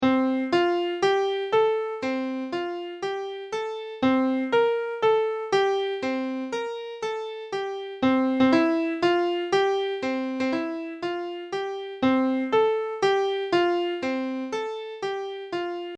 Repeat after me song:
Tradional Song